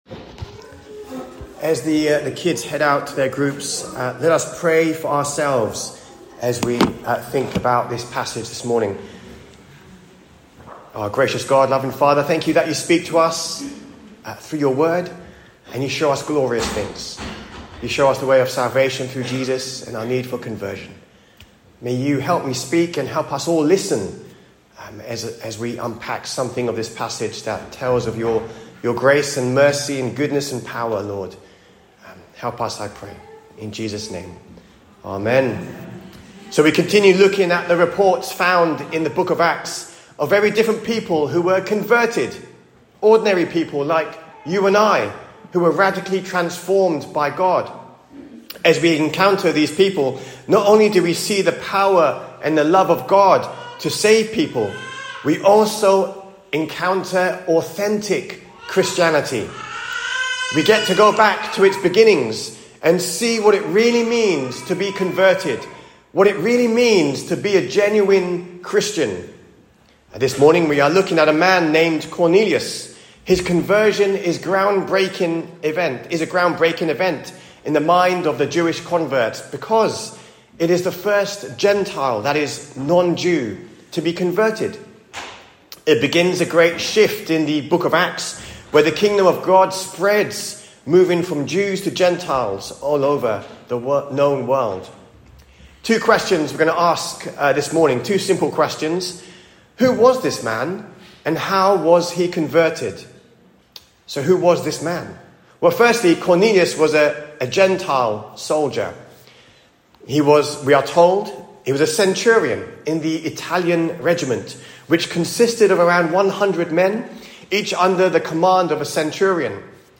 SERMON-16TH-FEBRUARY.mp3